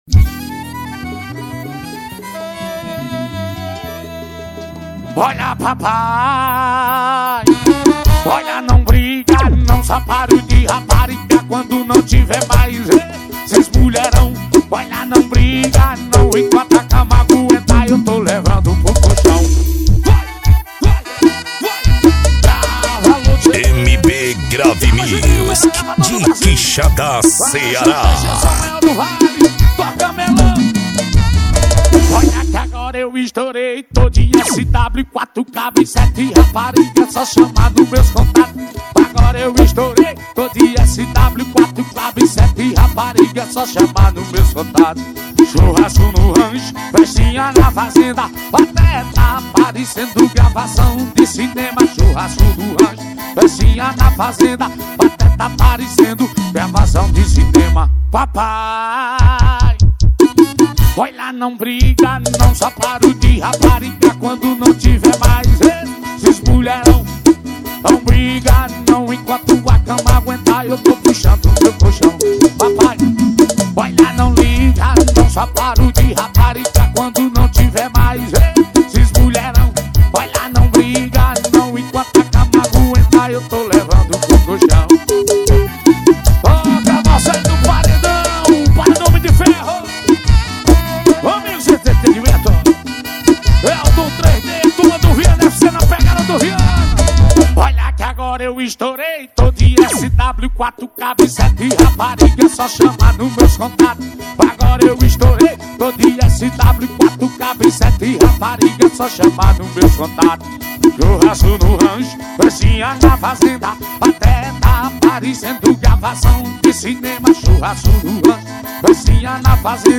2025-02-02 20:21:09 Gênero: Forró Views